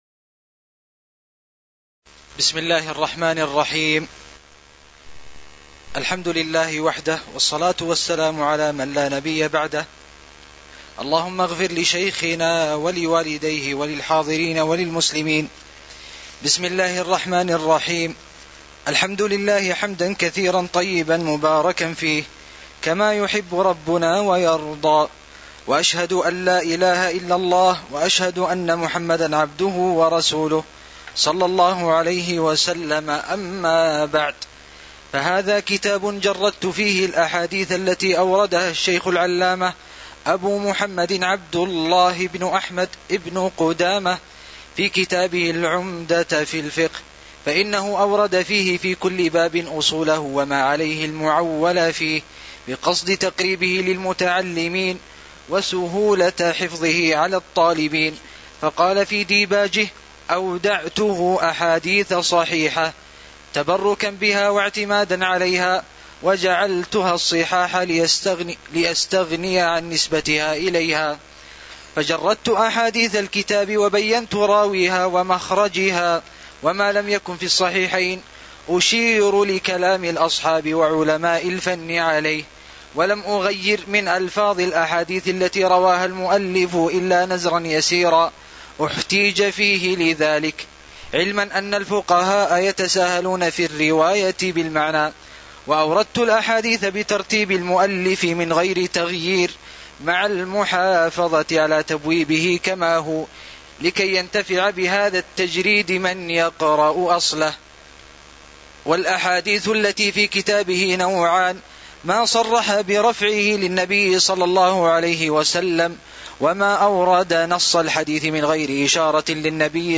تاريخ النشر ٢٤ جمادى الآخرة ١٤٤٠ هـ المكان: المسجد النبوي الشيخ